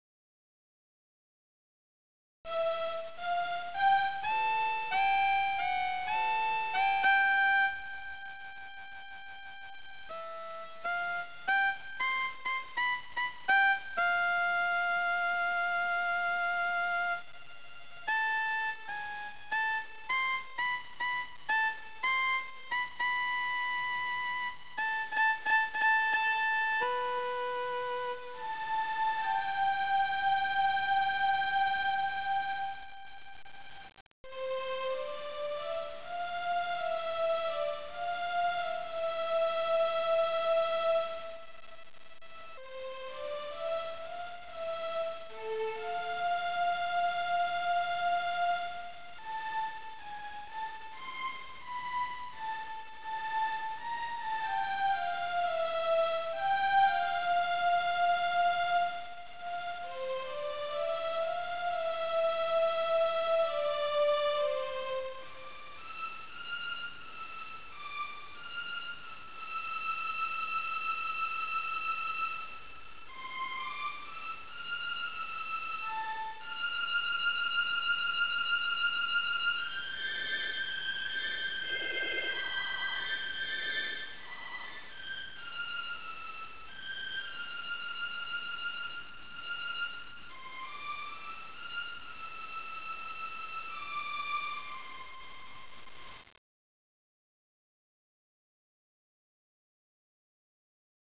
"サビ"のメロディをかなりアバウトに入力、聴くことができる。